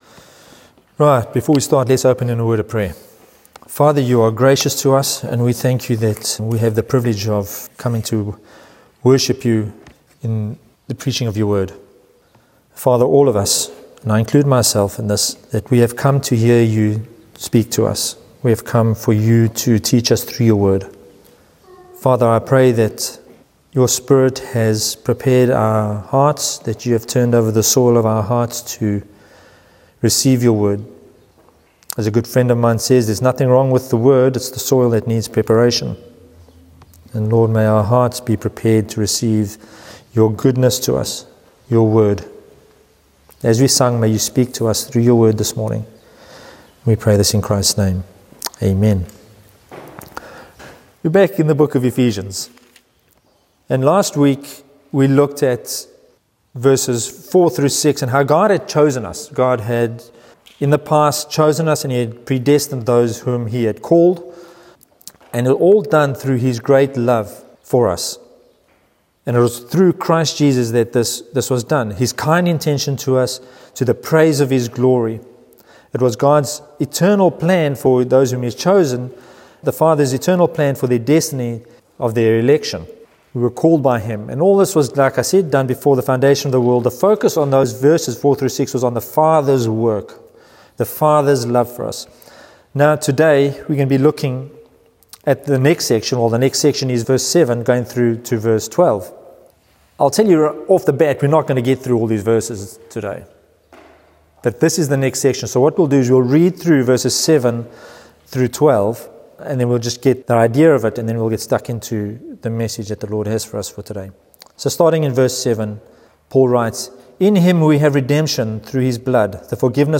In this sermon we come to Ephesians 1:7–8a and focus on the first aspect of the Son’s saving work: redemption received, leading to forgiveness of sins.